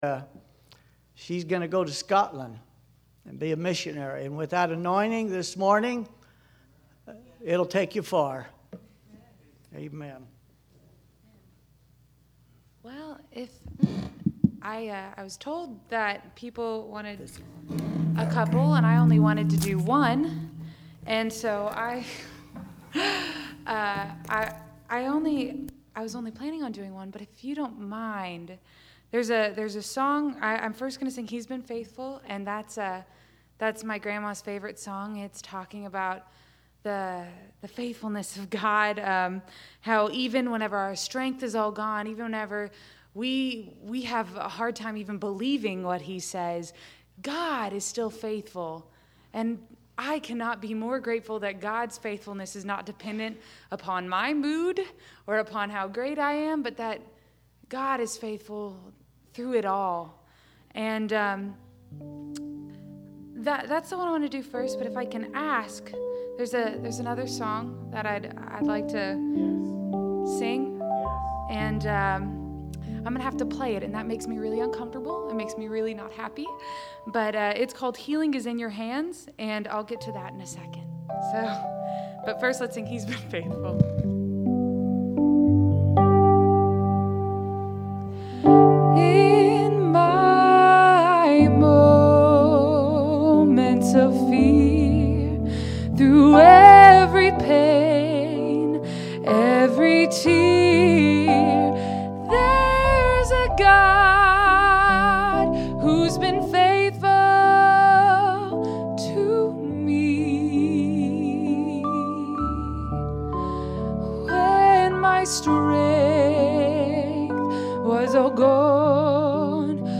Special Song